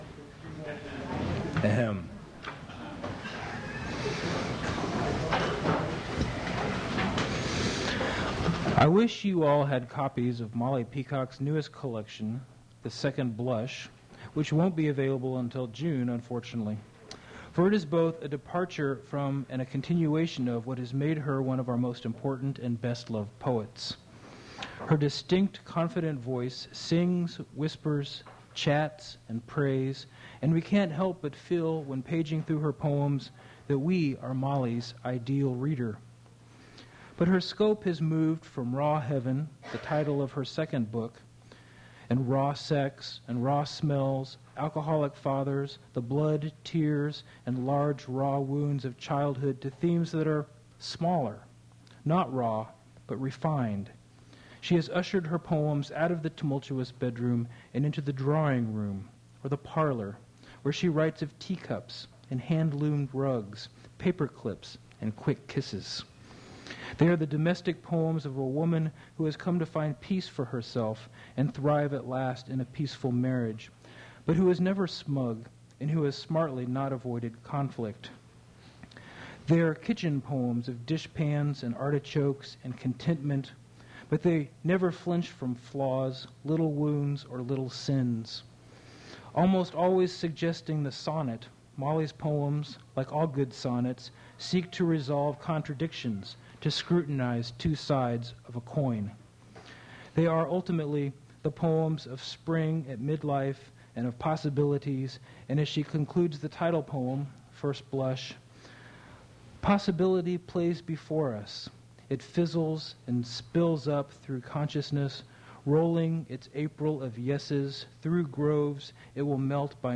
Attributes Attribute Name Values Description Molly Peacock poetry reading at Duff's Restaurant.
Source mp3 edited access file was created from unedited access file which was sourced from preservation WAV file that was generated from original audio cassette. Language English Identifier CASS.862 Series River Styx at Duff's River Styx Archive (MSS127), 1973-2001 Note Recording ends in the middle of the last poem.